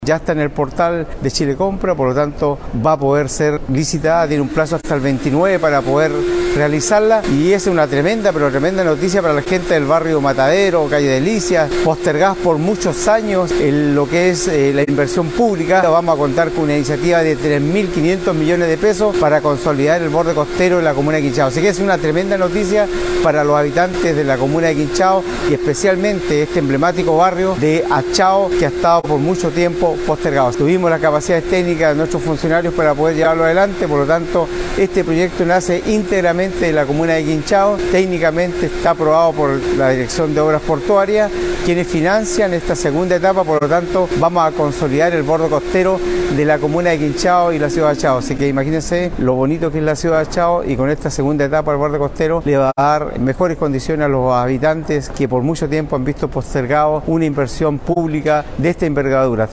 El edil se refirió, además a los plazos de la licitación que ya está en el portal de Chile Compra: